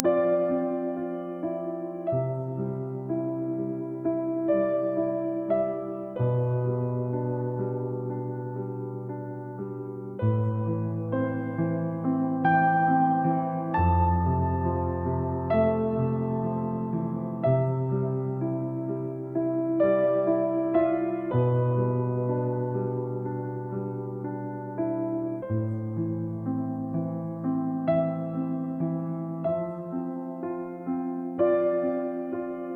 A slow and minimalist piano solo for a late night when you are completely exhausted from work and chores.
The atmosphere is warm, gentle, and quiet. Slow tempo, very few notes, plenty of silence between sounds. High-quality acoustic grand piano.
AI(Google Lyria)が作ったピアノ曲 (0分32秒)